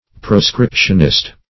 Proscriptionist \Pro*scrip"tion*ist\
proscriptionist.mp3